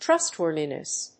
/ˈtrʌˌstwɝðinʌs(米国英語), ˈtrʌˌstwɜ:ði:nʌs(英国英語)/
音節trúst･wòrthiness